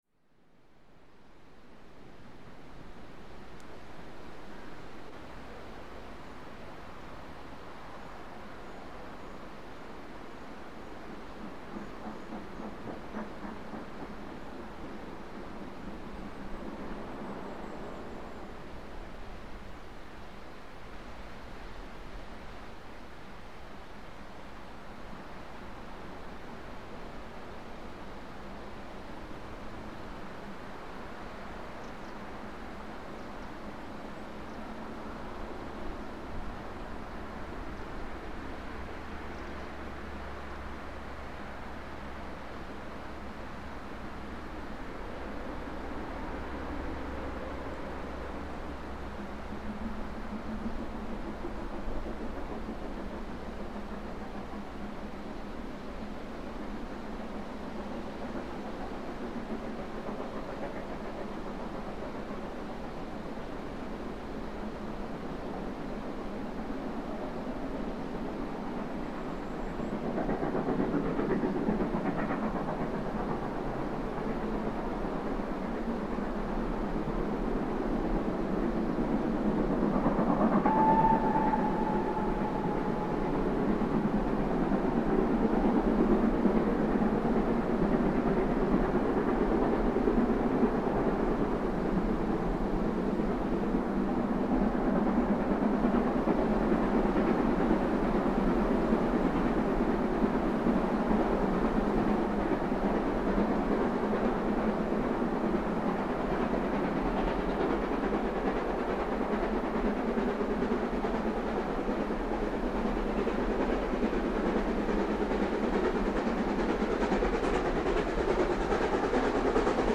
erst 44767 mit Zug Richtung Pickering abwärts rollend, dann
80135 Tv bergauf Richtung Grosmont fahrend, aufgenommen im Newton Dale weit oberhalb Levisham im Wald, um 12:47h am 13.08.2000.   Hier anhören: